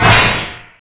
SI2 CRUNCH.wav